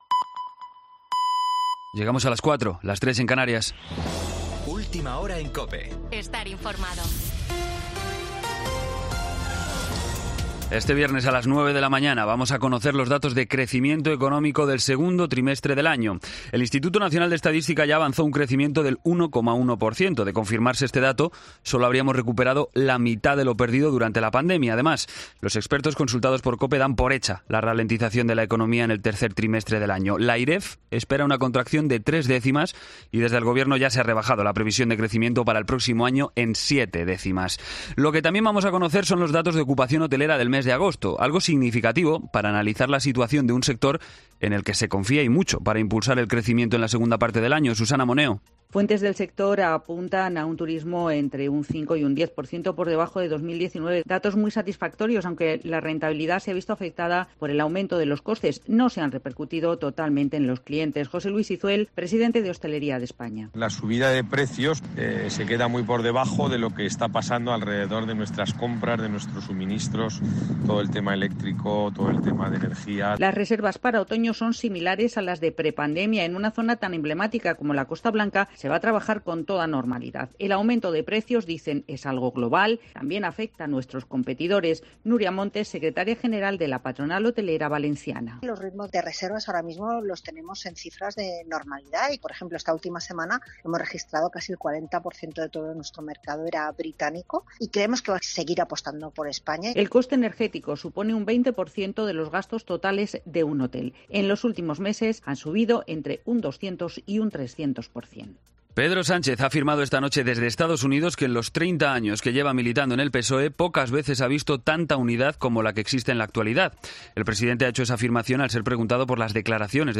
Boletín de noticias COPE del 23 de septiembre a las 04:00 hora
AUDIO: Actualización de noticias Herrera en COPE